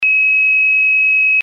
ZUMBADOR - SONIDO CONTINUO
Zumbador de fijación mural
Sonido continuo
90dB